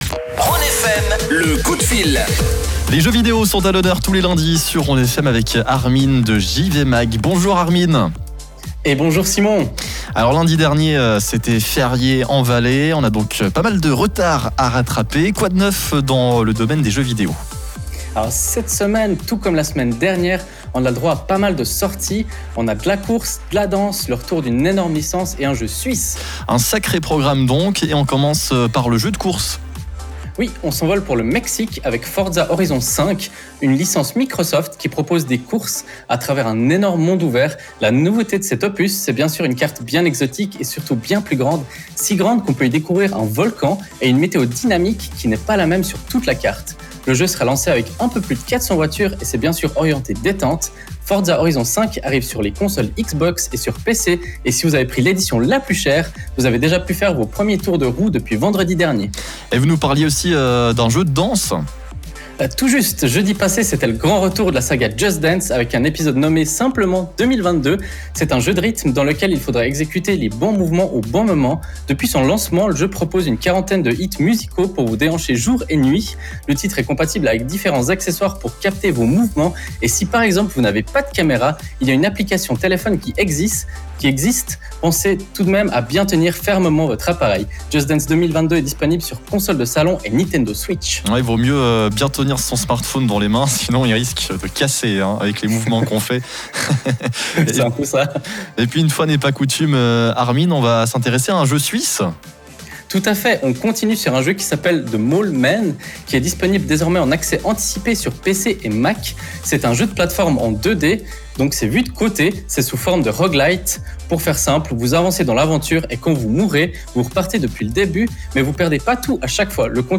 On reprend du service après une semaine de pause sur la radio Rhône FM avec notre chronique gaming. Pour cette douzième édition, on parle des quelques grosses sorties du moment.